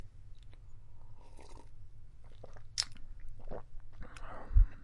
人类的声音 " 抿了一口
用Zoom H1记录。
Tag: 男性 苏打 啜食 饮料 液体